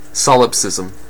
Solipsism (/ˈsɒlɪpsɪzəm/
En-us-ncalif-solipsism.ogg.mp3